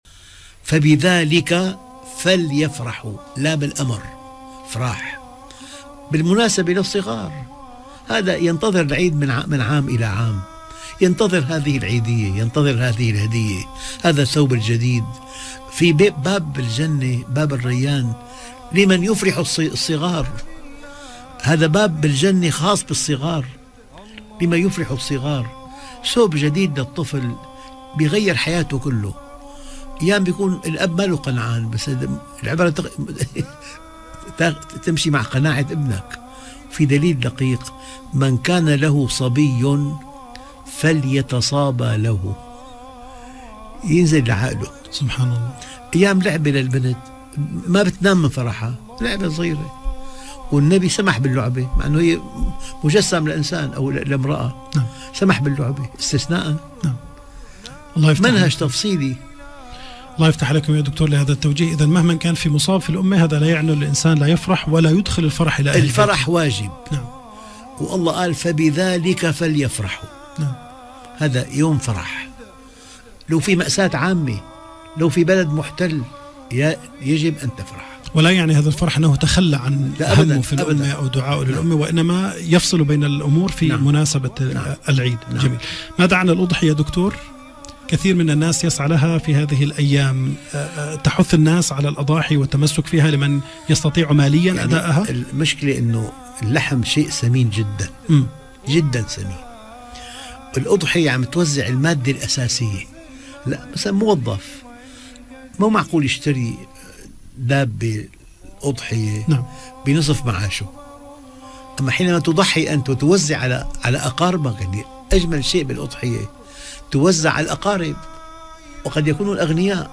... درس هااااام ...
للدكتور محمد راتب النابلسي